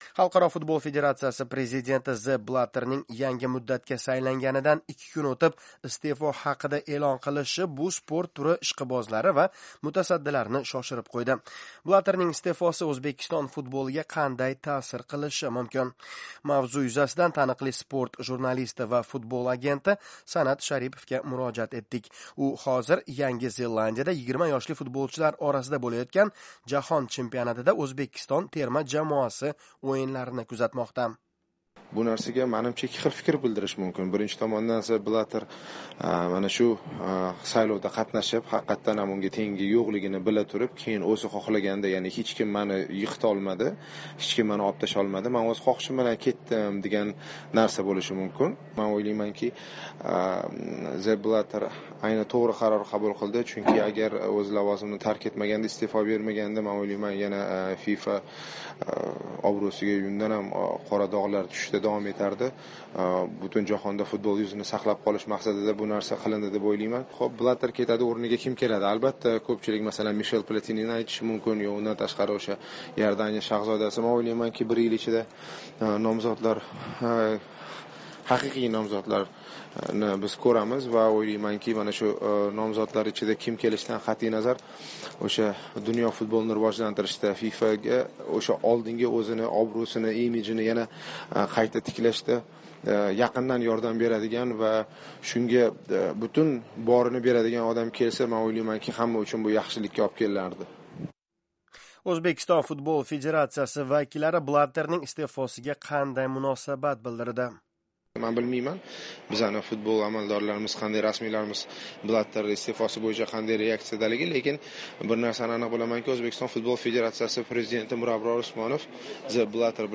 suhbatni